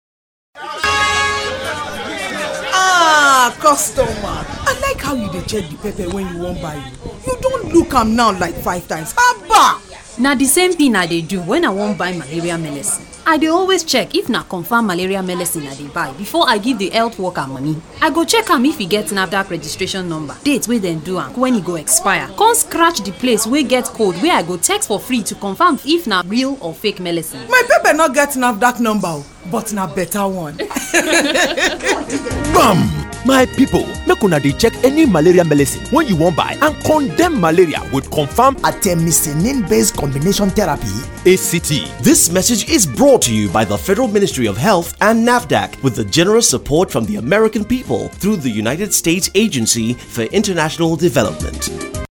Pidgin-Pepper-Seller-Final-Approved-1.mp3